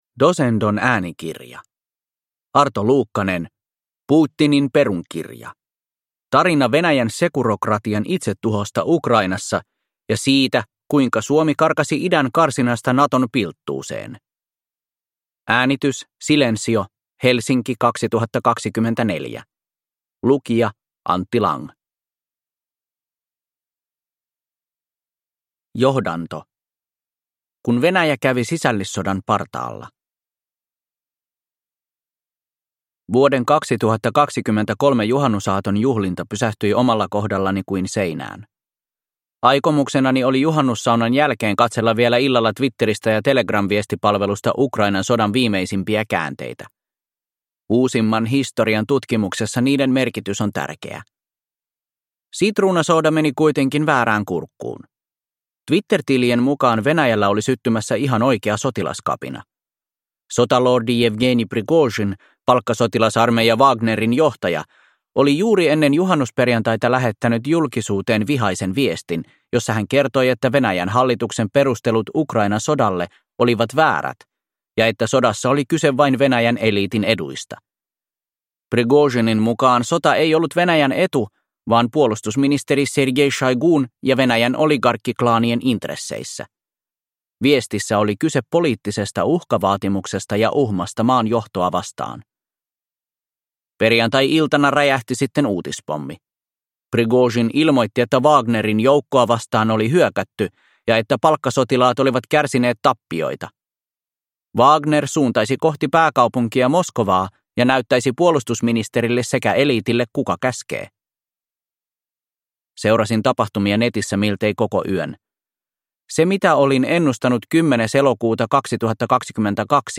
Putinin perukirja (ljudbok) av Arto Luukkanen